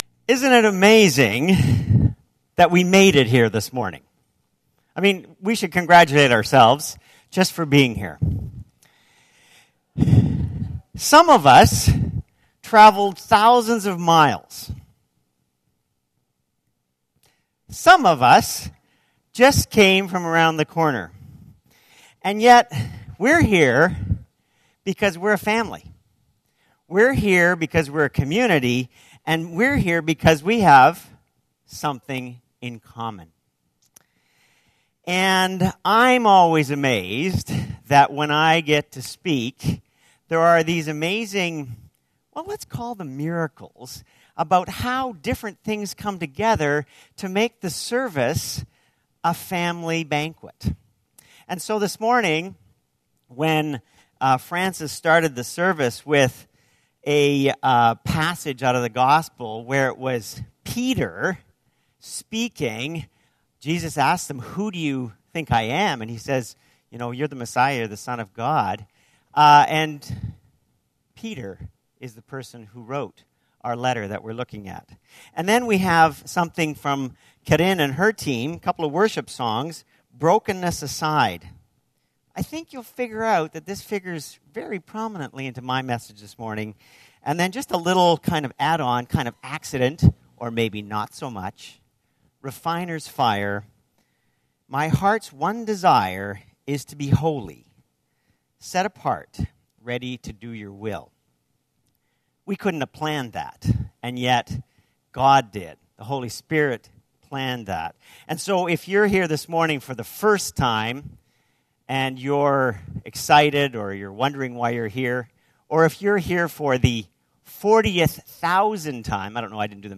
This sermon is based on 1 Pet 1:10-19.